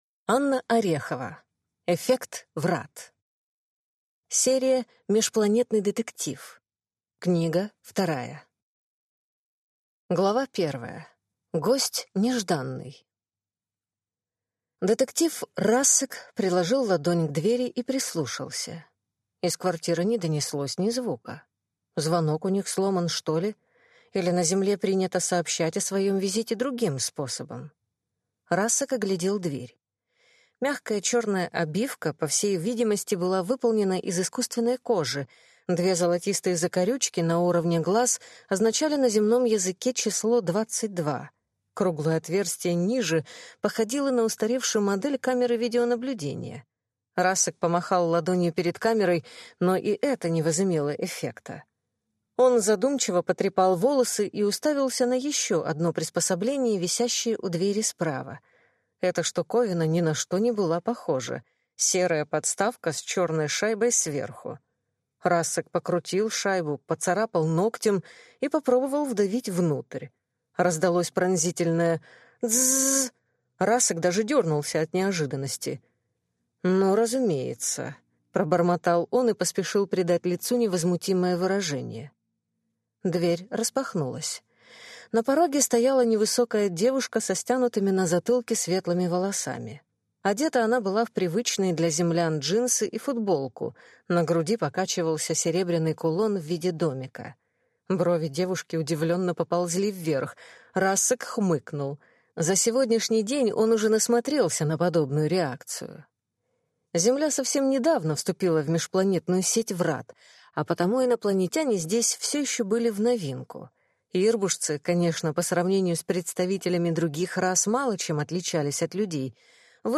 Аудиокнига Эффект Врат | Библиотека аудиокниг